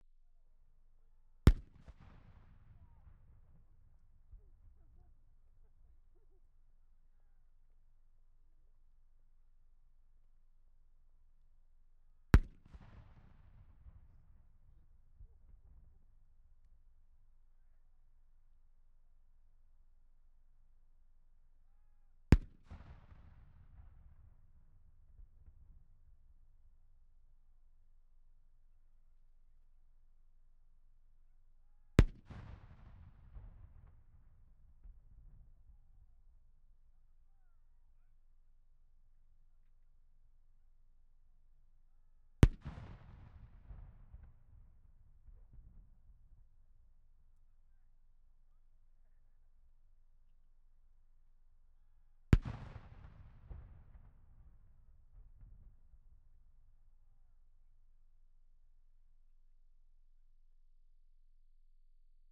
Cannons 2
Please note that this recording is included purely for the sake of interest; i.e. this is what happens when you position a microphone about fifty feet away from the front of a WWI field gun firing blank charges. It's not meant to be a great recording, but it does show how well the microphone can cope with that sort of abuse. This is a recording of the six guns firing in order. You can hear how the initial pressure wave diminishes as the distance and angle increases. High level playback will probably harm your speakers - don't blame me!
Map    London, England, United Kingdom
Ambisonic order: F (4 ch) 1st order 3D
Microphone name: Core Sound TetraMic
Array type: Tetrahedral
Capsule type: Cardioid